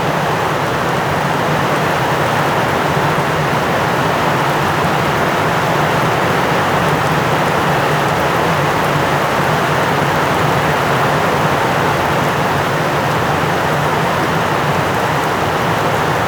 Heavy Rain Ambient Loop 2.wav